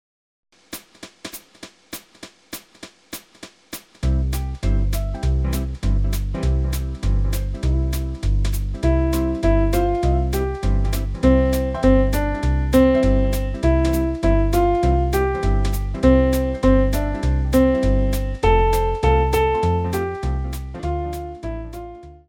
Podgląd piosenki bez wokalu: